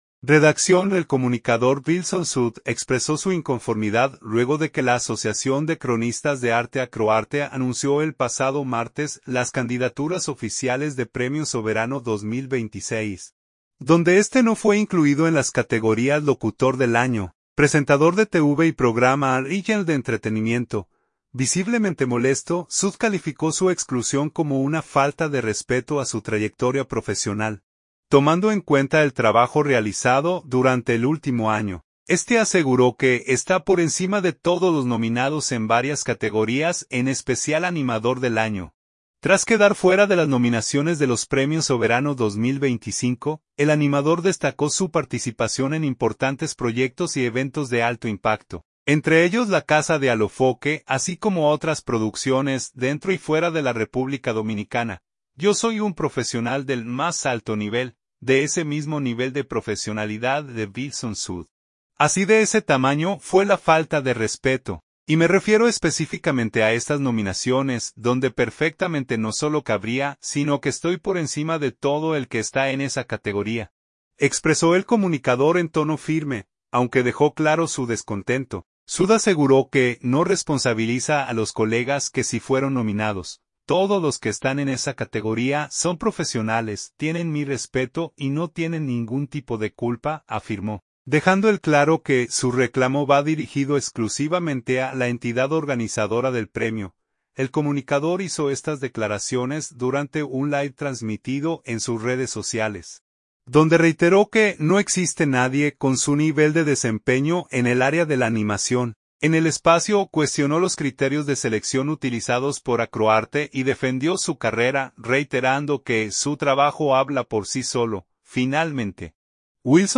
Visiblemente molesto, Sued calificó su exclusión como una falta de respeto a su trayectoria profesional, tomando en cuenta el trabajo realizado durante el último año.
El comunicador hizo estas declaraciones durante un live transmitido en sus redes sociales, donde reiteró que no existe nadie con su nivel de desempeño en el área de la animación.